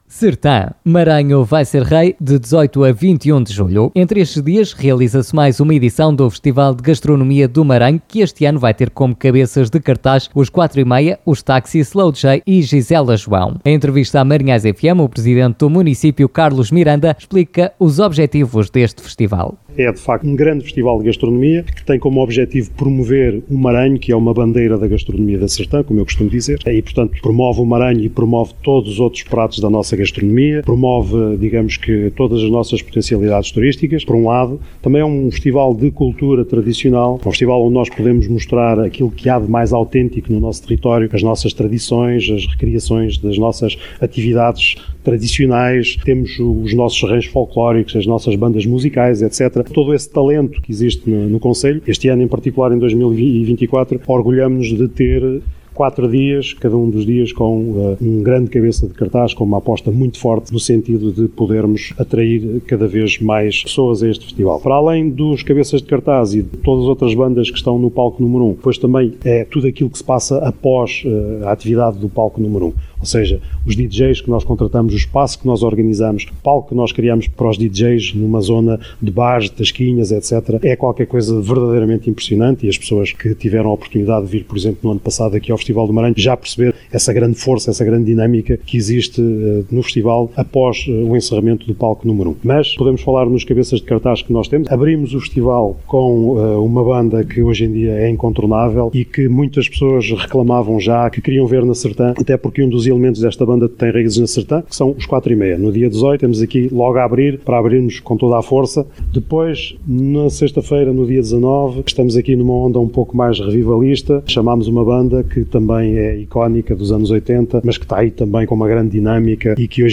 Escute, aqui, as declarações de Carlos Miranda, Presidente da Câmara Municipal da Sertã: